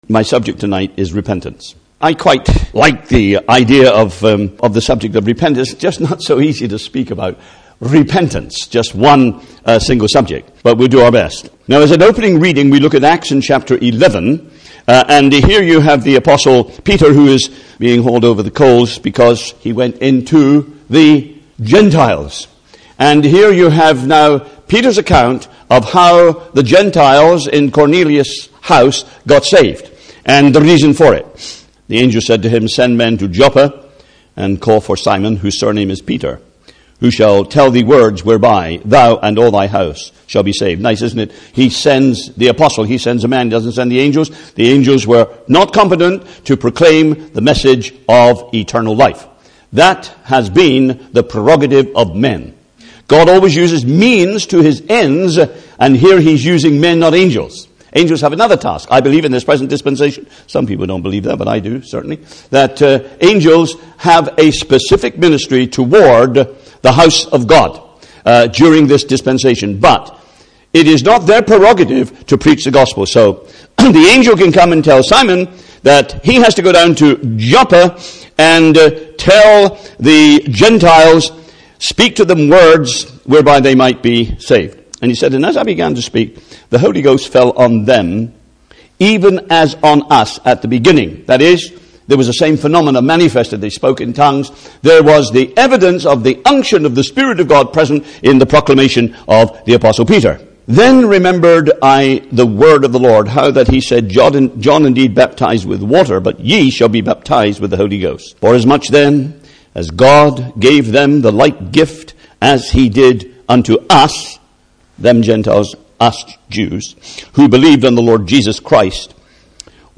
He contends there is no salvation without it. He defines the meaning and relevance of this subject in today’s evangelism (Message preached 12th June 2008)